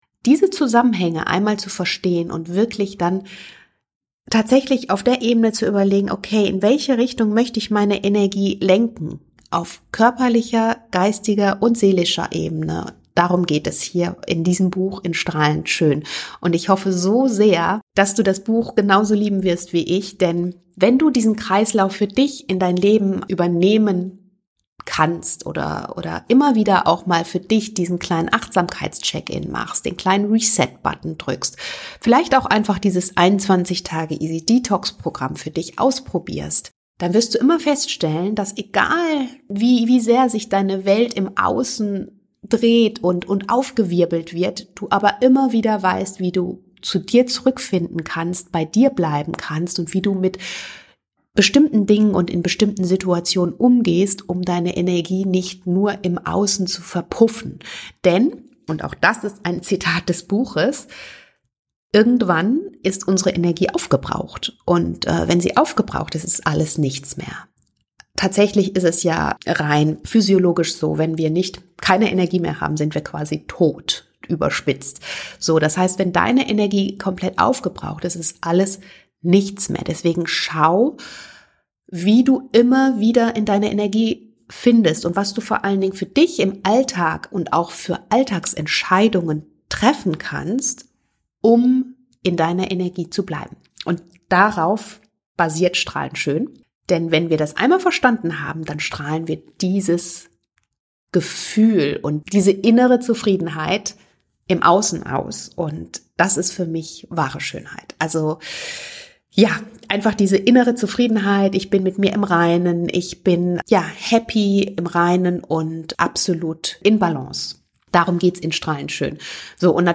Der Podcast für all diejenigen, die sich nach einem ganzheitlich gesunden und glücklichen Leben in Balance sehnen.